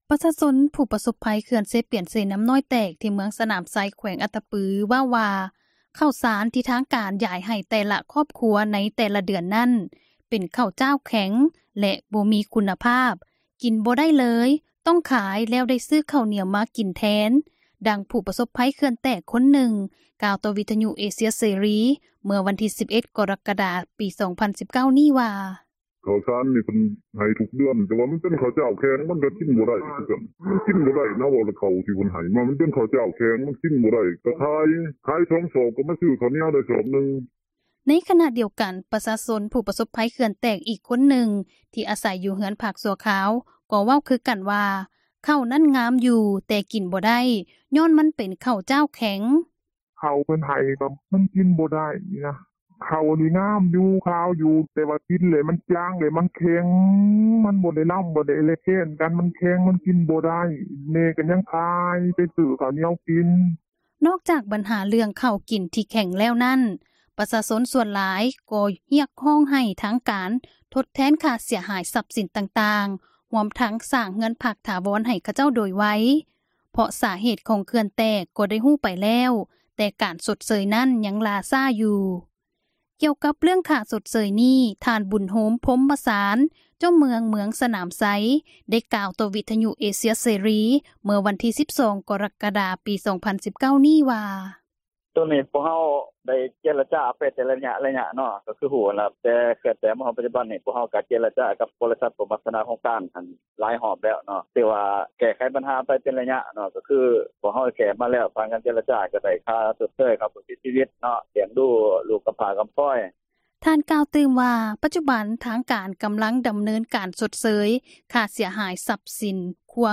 ປະຊາຊົນຜູ້ປະສົບພັຍເຂື່ອນເຊປຽນ-ເຊນ້ຳນ້ອຍແຕກ ທີ່ເມືອງສະໜາມໄຊ ແຂວງອັດຕະປື ເວົ້າວ່າ ເຂົ້າສານ ທີ່ທາງການ ຢາຍໃຫ້ແຕ່ລະ ຄອບຄົວ ໃນແຕ່ລະເດືອນນັ້ນ ເປັນເຂົ້າຈ້າວ ແຂງ ແລະບໍ່ມີ ຄຸນນະພາບ ກິນບໍ່ໄດ້ເລີຍ ຕ້ອງຂາຍແລ້ວ ຊື້ເຂົ້າໜຽວ ມາກິນແທນ, ດັ່ງຜູ້ ປະສົບພັຍ ເຂື່ອນແຕກຄົນນຶ່ງ ກ່າວຕໍ່ວິທຍຸເອເຊັຽເສຣີ ເມື່ອວັນທີ່ 11 ກໍຣະກະດາ ປີ 2019 ນີ້ວ່າ:
ໃນຂນະດຽວກັນ ປະຊາຊົນຜູ້ປະສົບພັຍເຂື່ອນແຕກ ອີກຄົນນຶ່ງ ທີ່ອາສັຍຢູ່ເຮືອນພັກຊົ່ວຄາວ ກໍເວົ້າຄືກັນວ່າ ເຂົ້ານັ້ນງາມຢູ່ ແຕ່ກິນບໍ່ໄດ້ ຍ້ອນມັນເປັນເຂົ້າຈ້າວແຂງ: